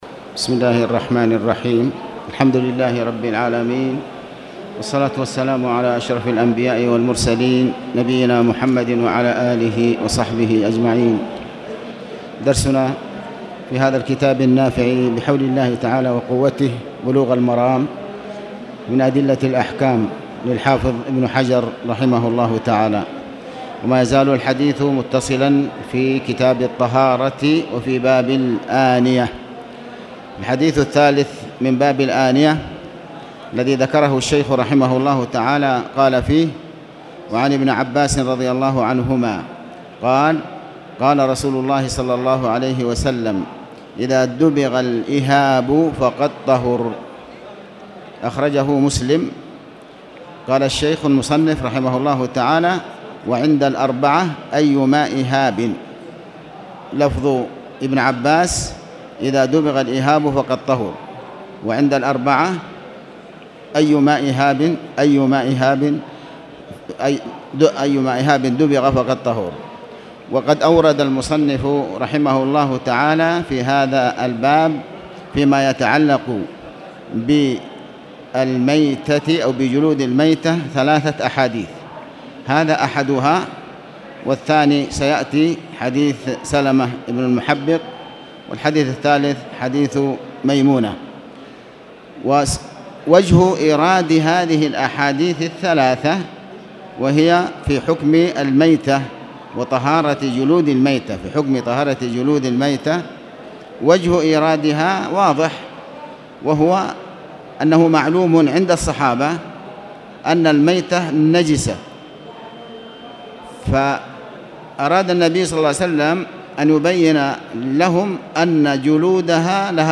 تاريخ النشر ٢٦ محرم ١٤٣٨ هـ المكان: المسجد الحرام الشيخ: علي بن عباس الحكمي علي بن عباس الحكمي كتاب الطهارة-باب الآنيه